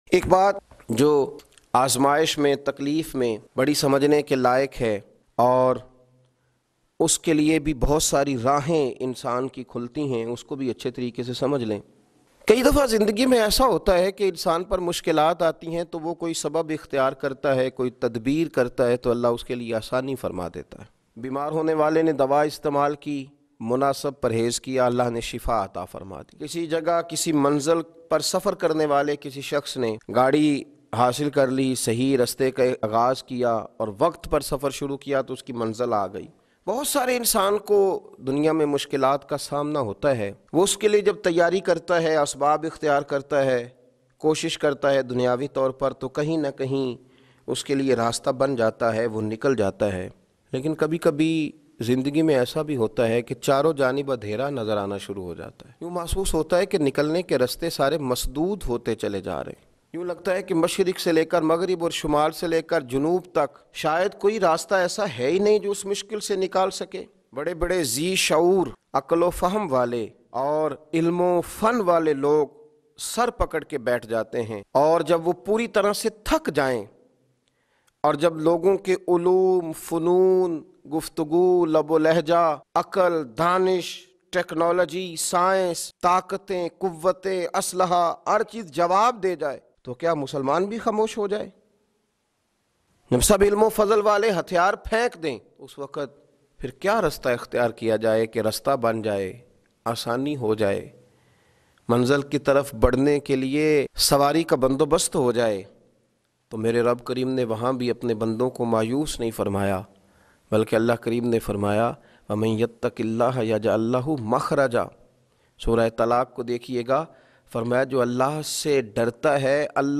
Jab Charon Taraf Andhera Cha Jay Bayan